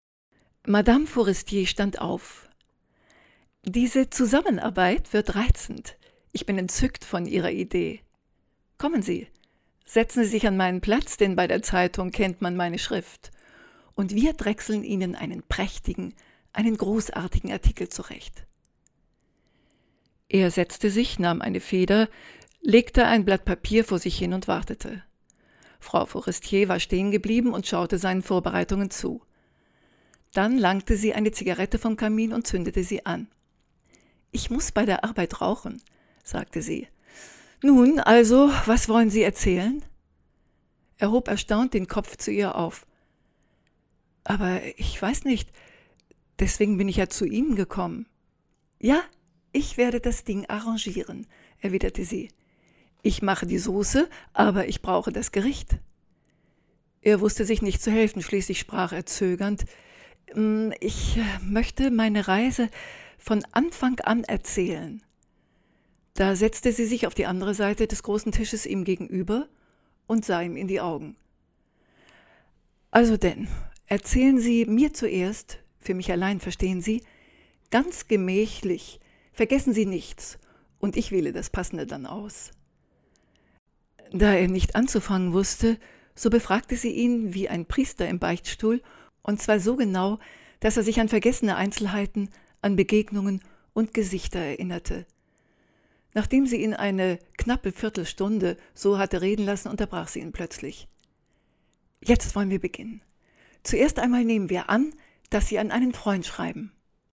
professionelle deutsche Sprecherin.
Sprechprobe: eLearning (Muttersprache):
german female voice over artist